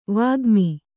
♪ vāgmi